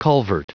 Prononciation du mot culvert en anglais (fichier audio)
Prononciation du mot : culvert
culvert.wav